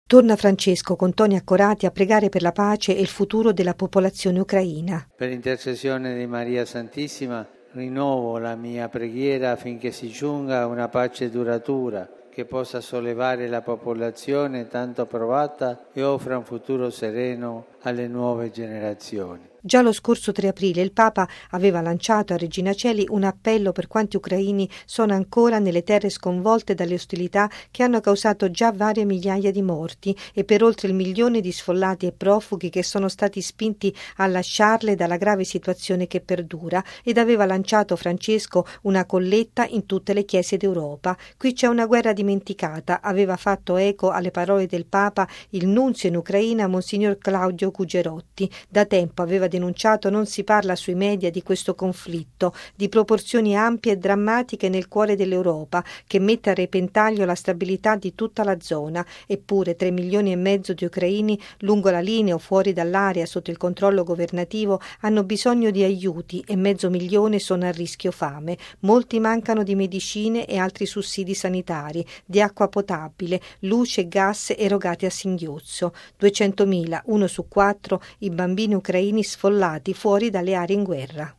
Una preghiera speciale per la pace in Ucraina è salita al cielo dalla voce di Papa Francesco, durante i saluti ai fedeli raccolti in Piazza San Pietro per l’udienza generale.
Torna Francesco, con toni accorati, a pregare per la pace e il futuro della popolazione ucraina: